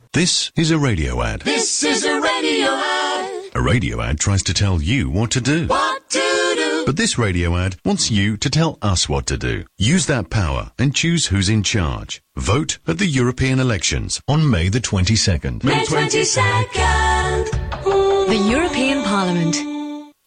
Nachrichten
17.06.2014Die Situation der hungerstreikenden Flüchtlinge in Calais spitzt sich zu. Anfang der Woche musste ein Flüchtling ins Krankenhaus gebracht werden wie ein anderer Hungerstreikende berichtet: